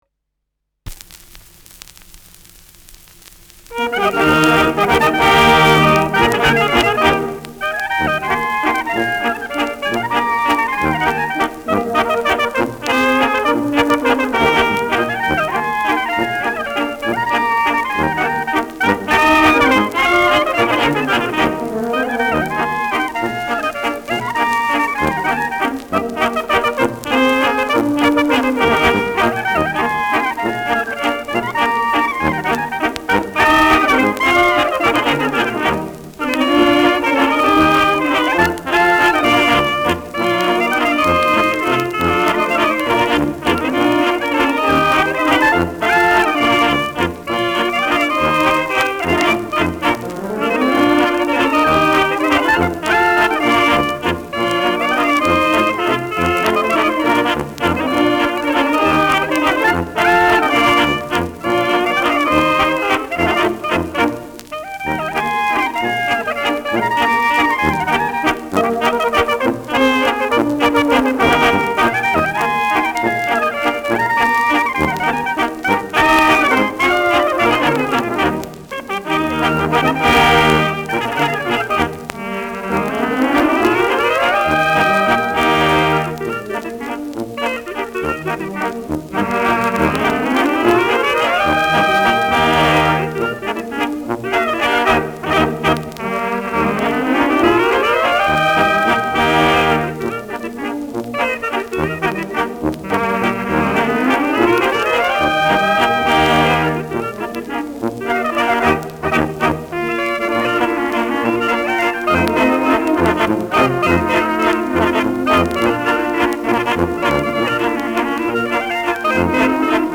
Schellackplatte
leichtes Knistern : leichtes Rauschen